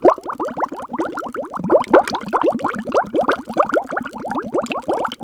GURGLE BUB03.wav